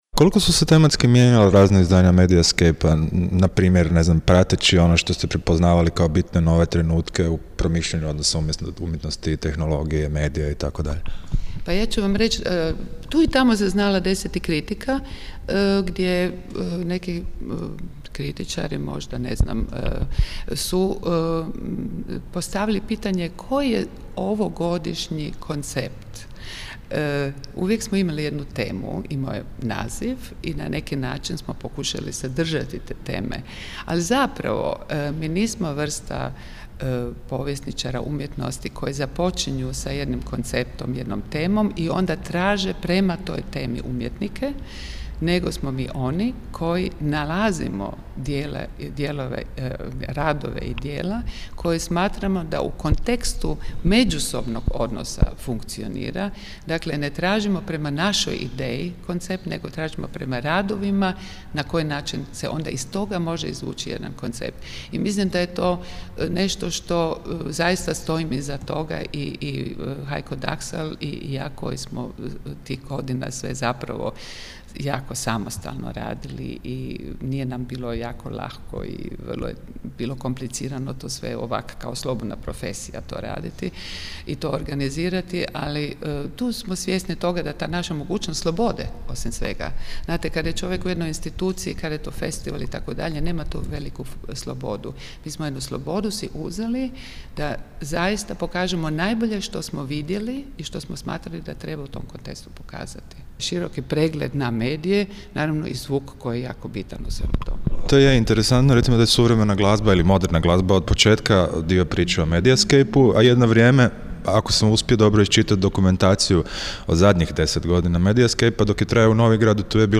JINGLE SUVREMENA UMJETNOST